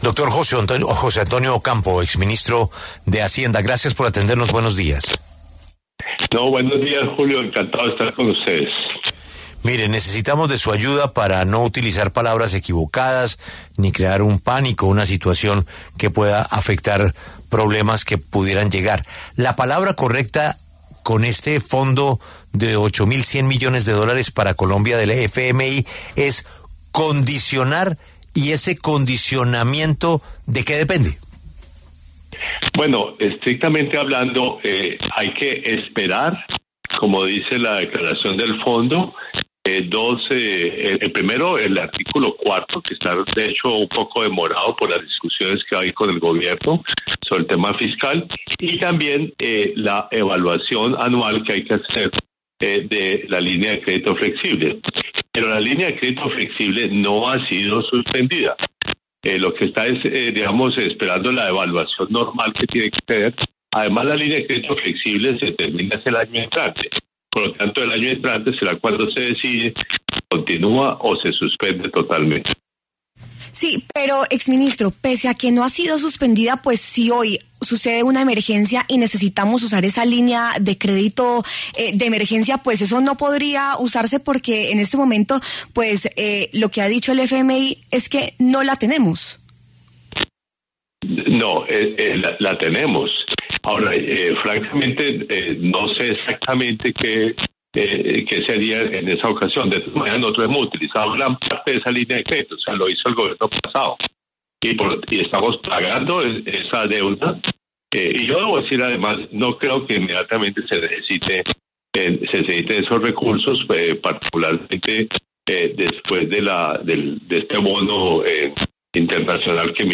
José Antonio Ocampo, exministro de Hacienda, habló en La W sobre las consecuencias del condicionamiento al que quedó continuar con la línea de crédito flexible otorgada por el Fondo Monetario Internacional (FMI), otorgada el año pasado y que se ha renovado desde el 2009.